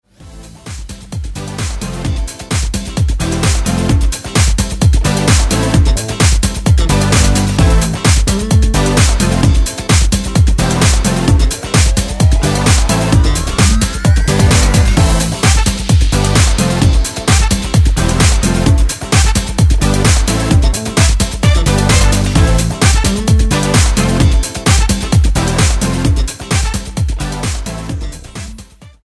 Happy, happy, happy…
All Around The World - 130 bpm 03.